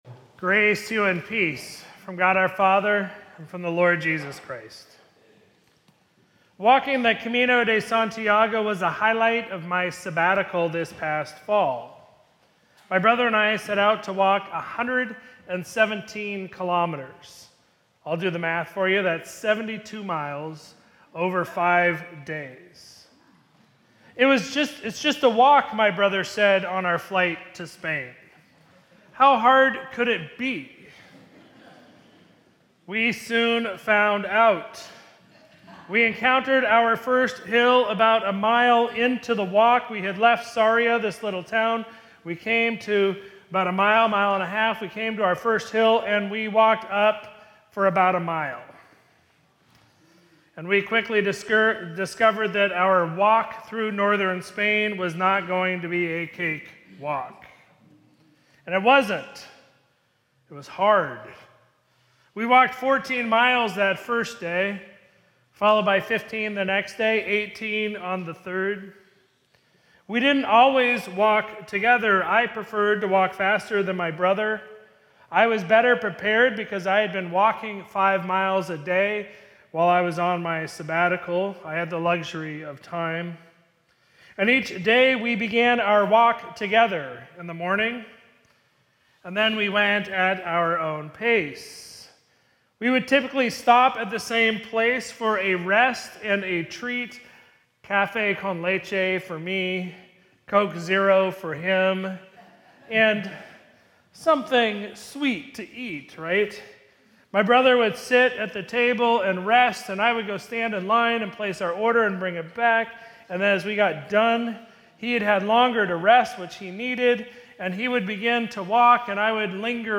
Sermon from Sunday, February 22, 2026